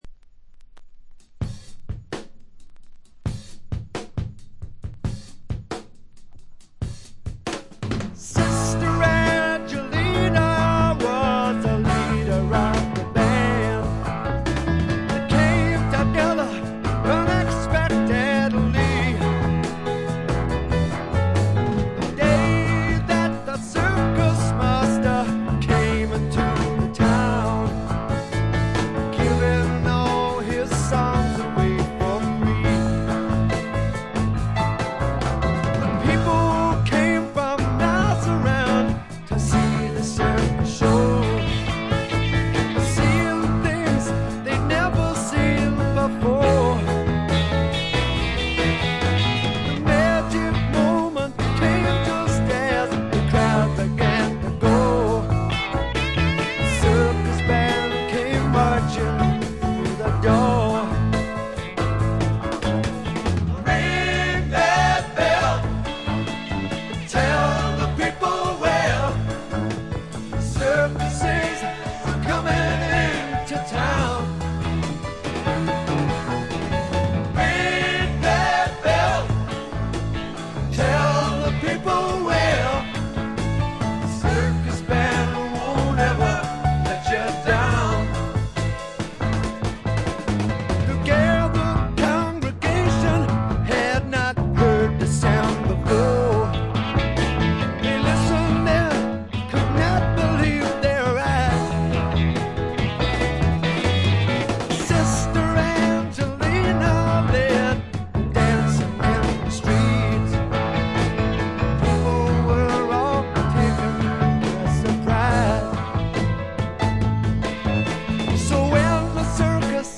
静音部で微細なチリプチが少し認められる程度でほとんどノイズ感無し。
ちょいと鼻にかかった味わい深いヴォーカルがまた最高です。
試聴曲は現品からの取り込み音源です。